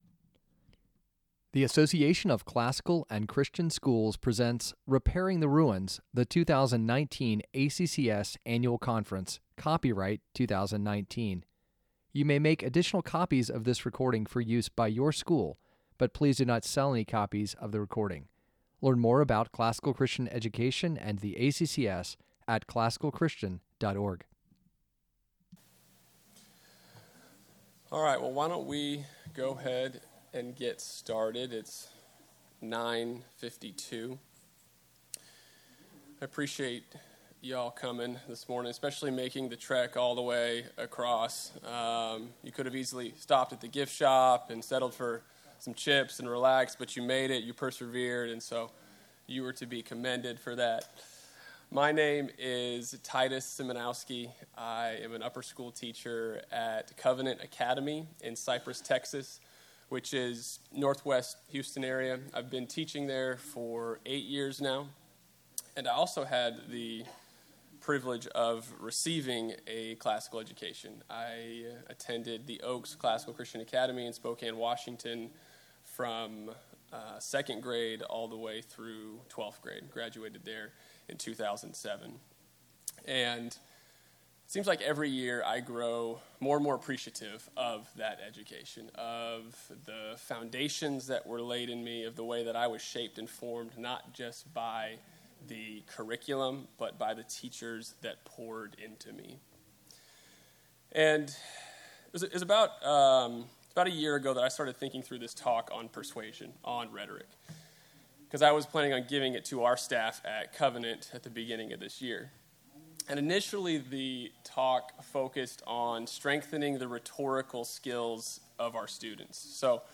2019 Workshop Talk | 51:25 | All Grade Levels, Rhetoric & Composition
Additional Materials The Association of Classical & Christian Schools presents Repairing the Ruins, the ACCS annual conference, copyright ACCS.